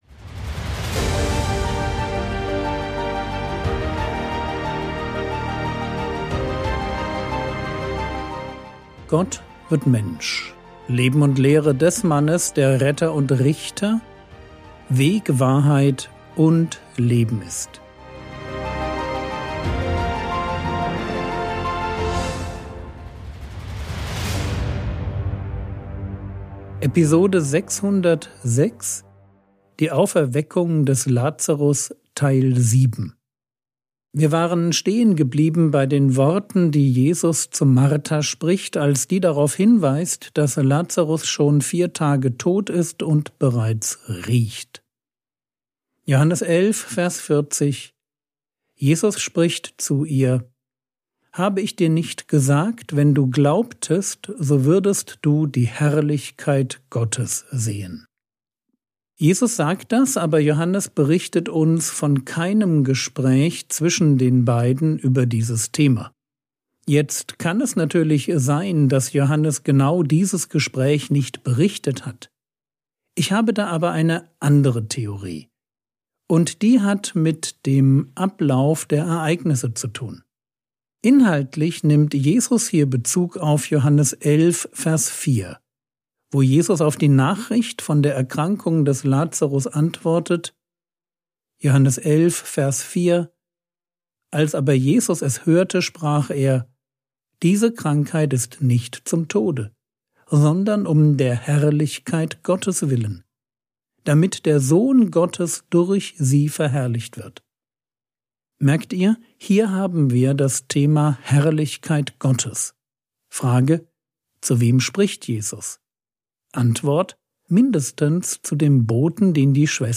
Episode 606 | Jesu Leben und Lehre ~ Frogwords Mini-Predigt Podcast